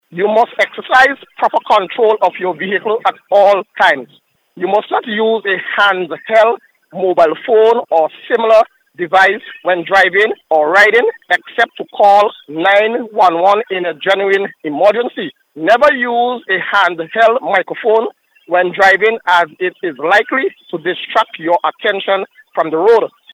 During the Traffic Highlights programme aired on NBC Radio